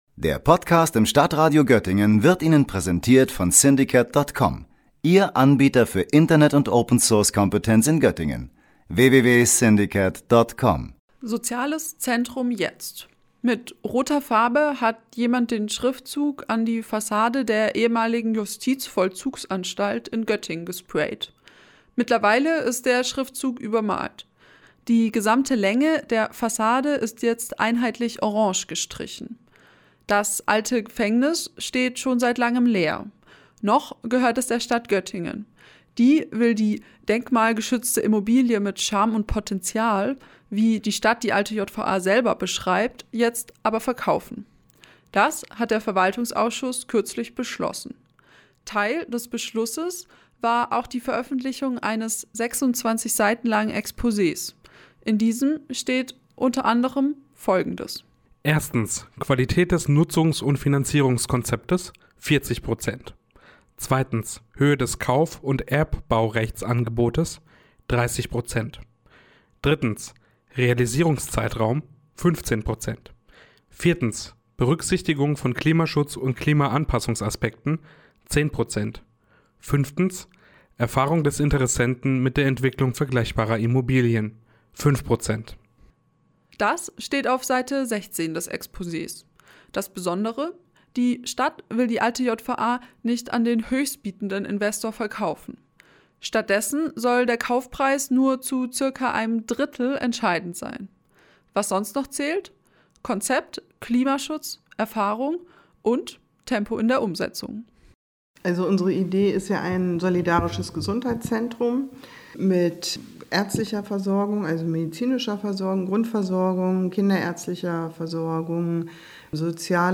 Beiträge > Die alte JVA wird verkauft: Stadt Göttingen veröffentlicht Verkaufskriterien - StadtRadio Göttingen